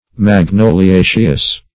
Search Result for " magnoliaceous" : The Collaborative International Dictionary of English v.0.48: Magnoliaceous \Mag*no`li*a"ceous\, a. (Bot.)